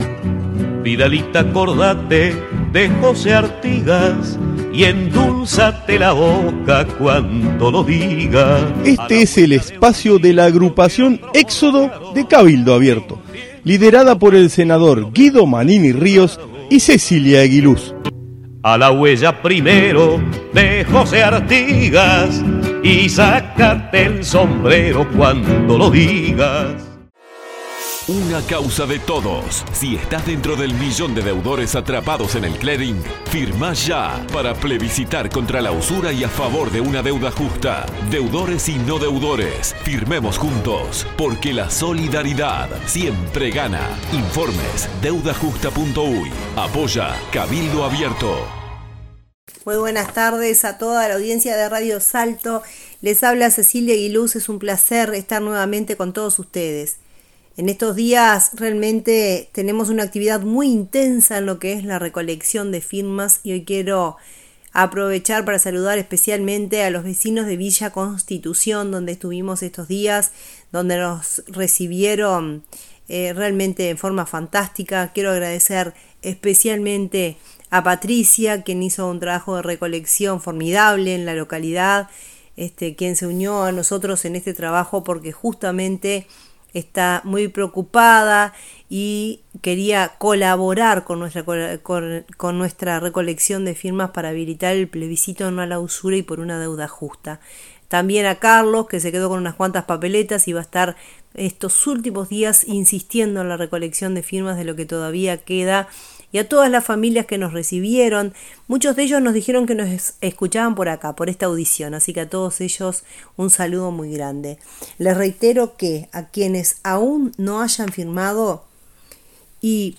Audición radial de nuestra agrupación para Radio Salto(1120AM) del día 18 de Abril de 2024.